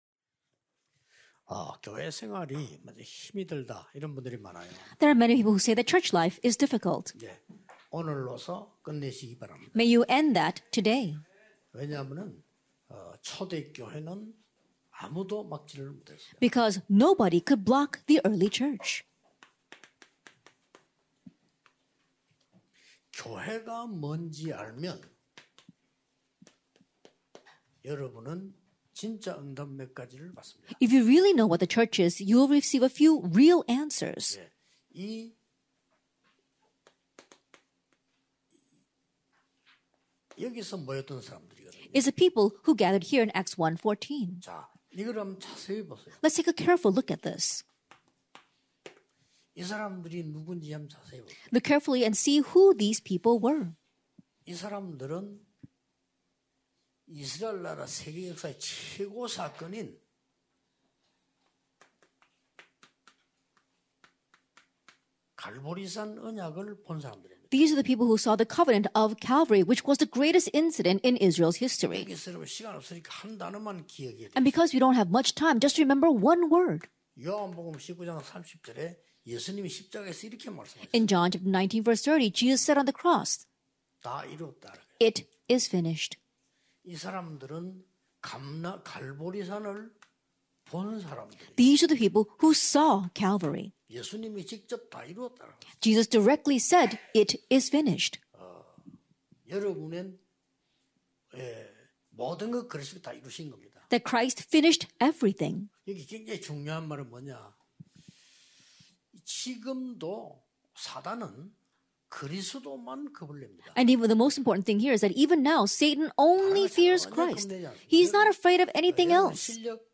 2022 New Year's Prayer Meeting Lec. 2 - Church 24hrs (Ac 2:1-4)